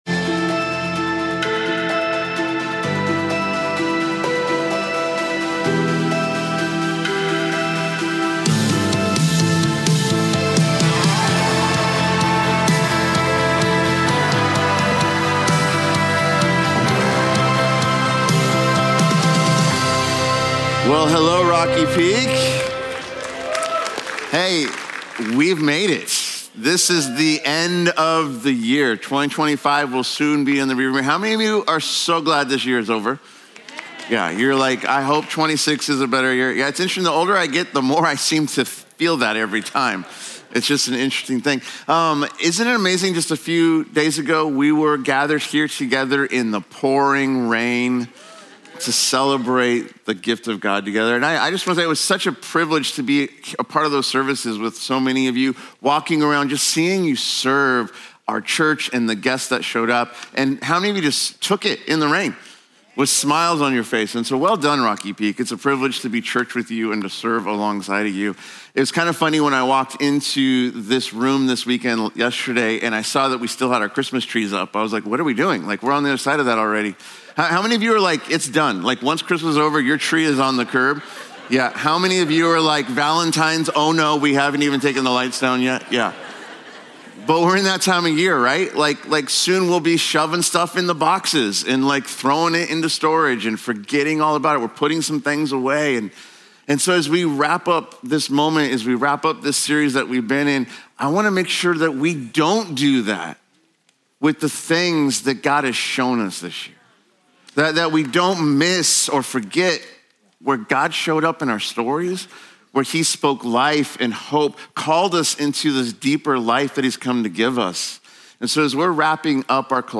… continue reading 992 episodes # Religion # Christianity # Rocky Peak # Messages # Gods # Jesus # Sermons # Church At Rocky Peak # Service